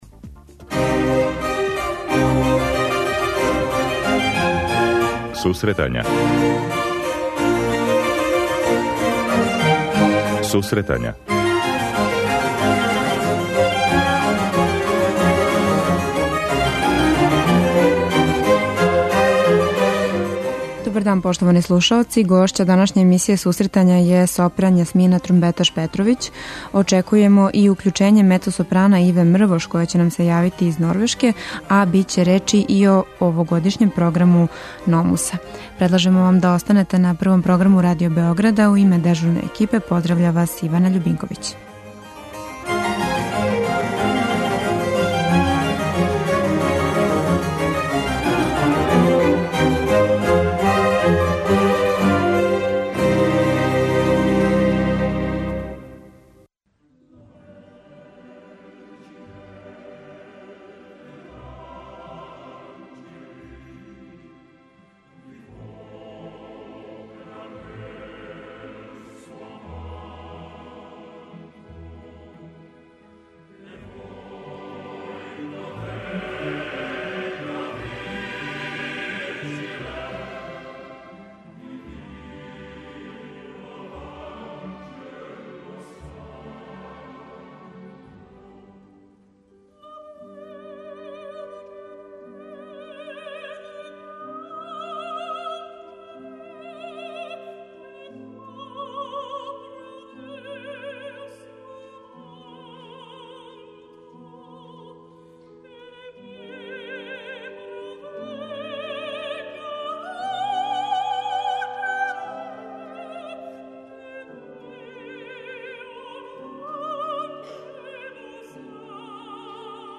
Очекујемо и телефонско укључење из Норвешке наше успешне младе уметнице - мецосопрана